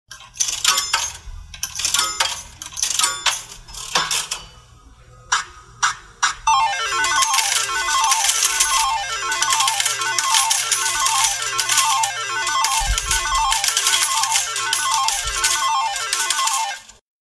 Playing And Winning Slot Machine Jackpot